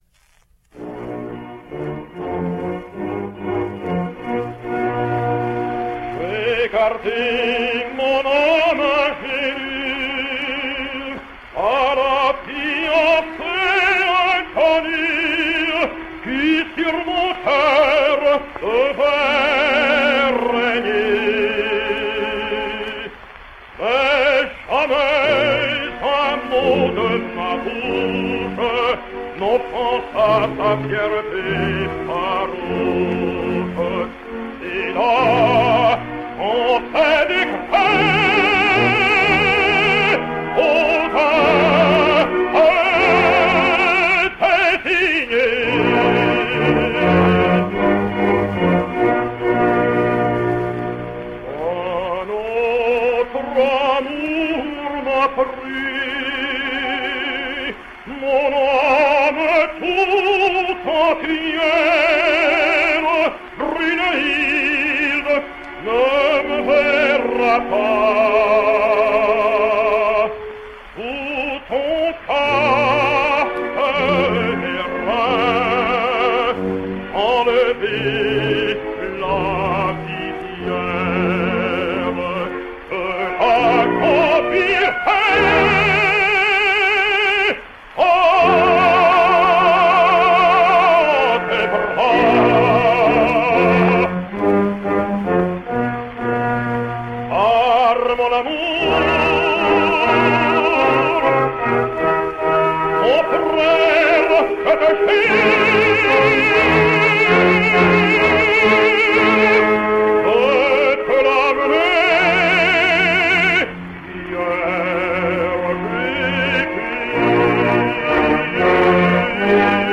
Corsican Tenor.
A heroic tenor who made his debut at the Opera Comique in 1911, he sang there until World War I engufed France, afterwards emerging at Marseille about 1920.
Aria / Sigurd / 1900 – César Vezzani